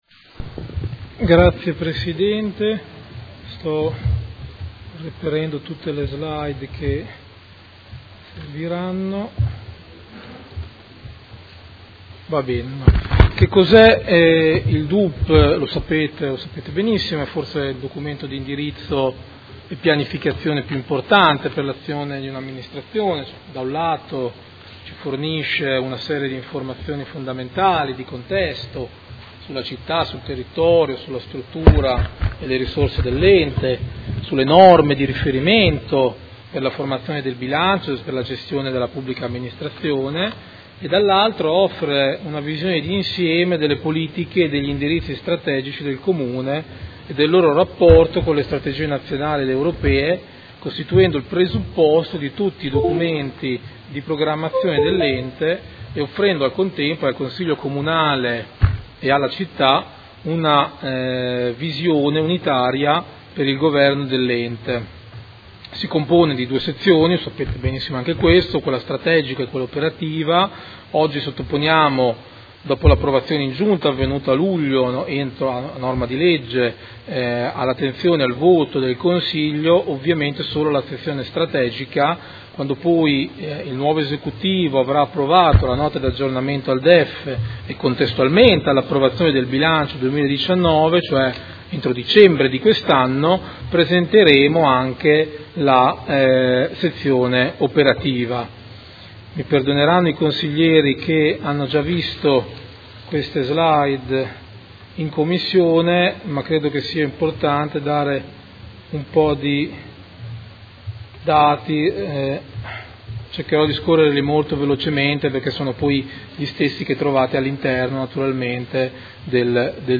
Seduta del 27/09/2018. Proposta di deliberazione: Documento Unico di Programmazione 2019-2021 – Approvazione